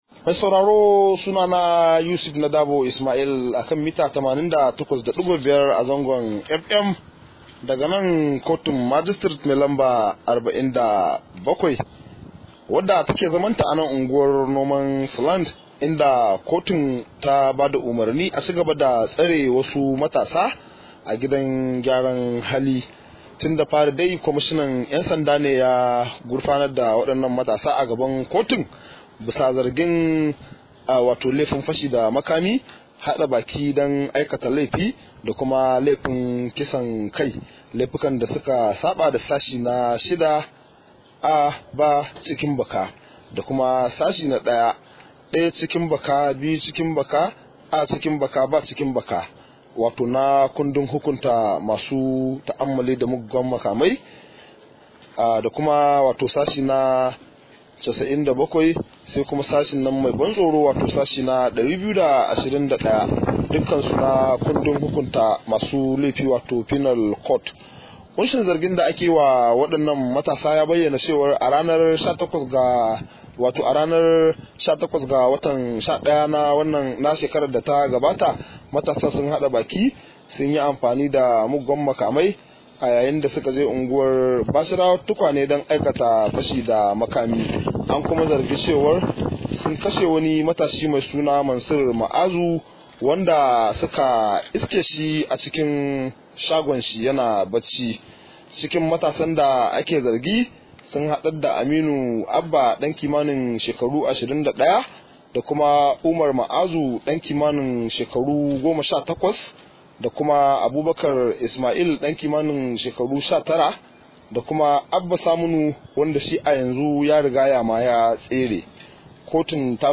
Rahoto: Kotu ta umarci a ci gaba da tsare matasan da ake zargi da kisan kai